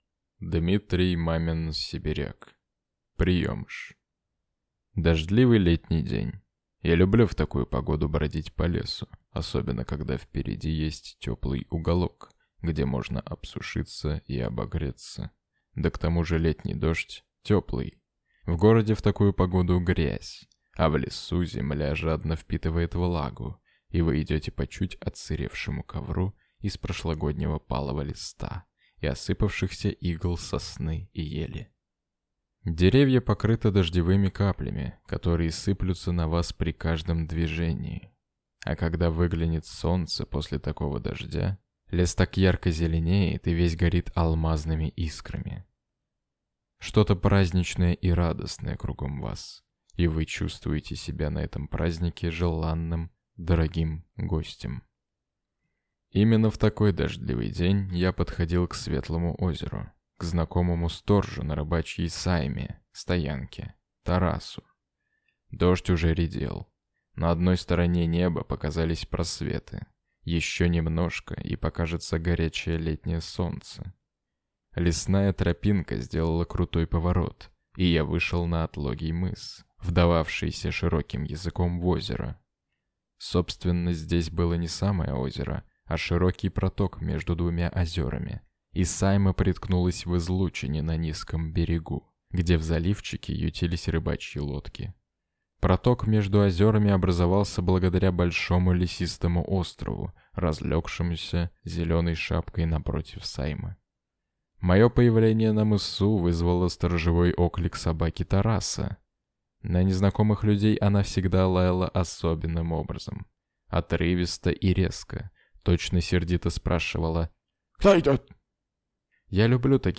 Аудиокнига Приемыш | Библиотека аудиокниг
Прослушать и бесплатно скачать фрагмент аудиокниги